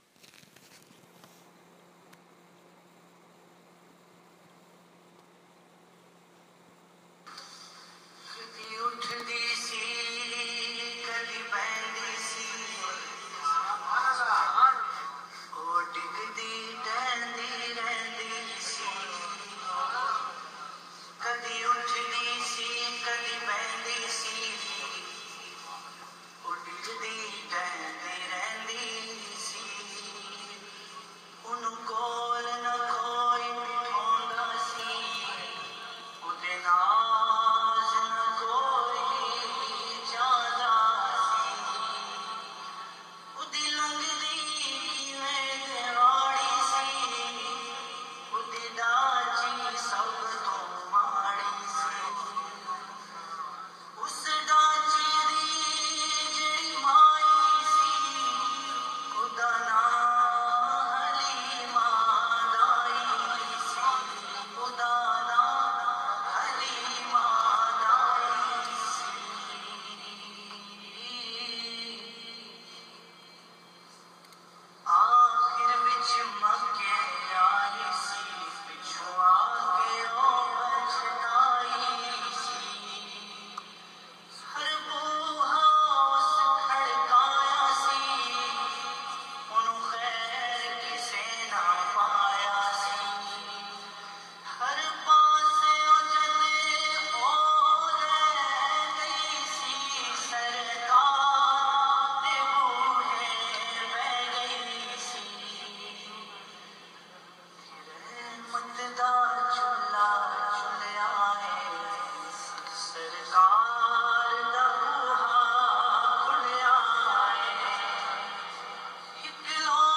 Dai Halima raa di shaan – Manqabat